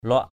/lʊaʔ/